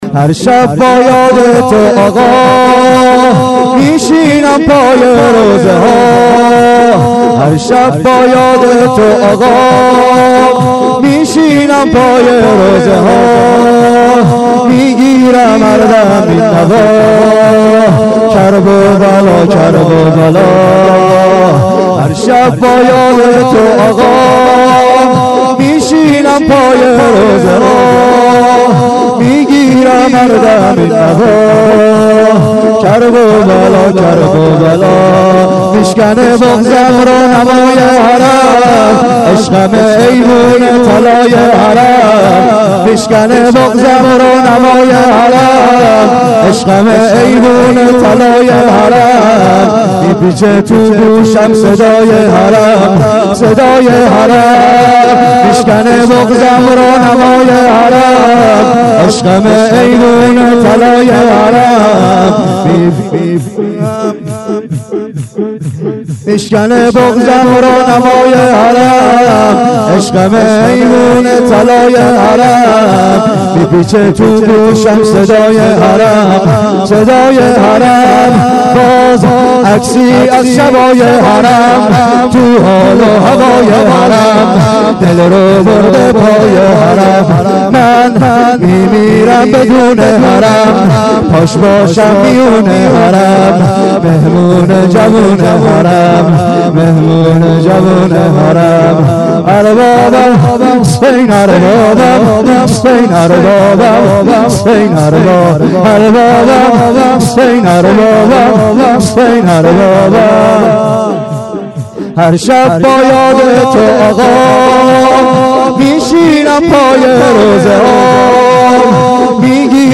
گلچین شورهای محرم 93
شور شب دوم : هرشب با یاده تو آقا میشینم پای روضه ها